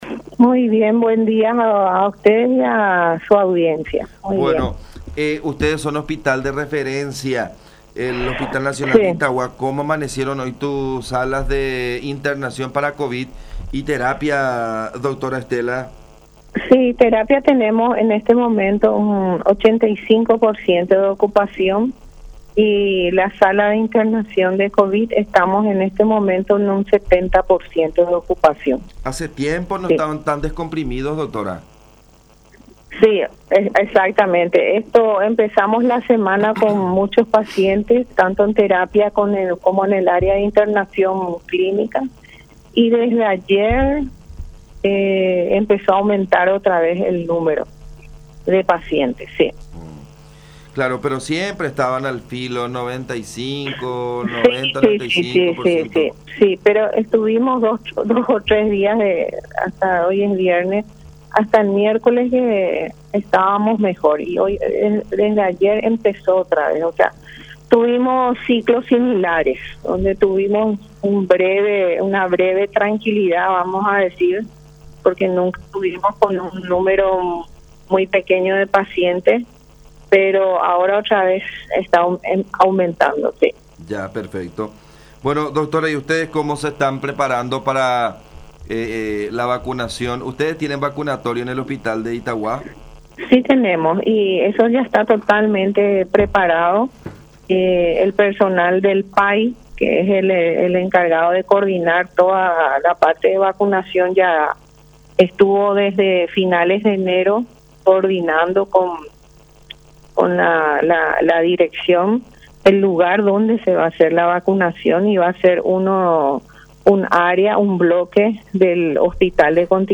“Empezamos la semana con muchos pacientes en ambos sectores”, detalló la profesional en conversación con La Unión, pero desde ayer empezó a subir de nuevo